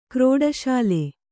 ♪ krōḍa sāle